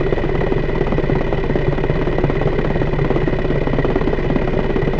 spaceEngineSmall_003.ogg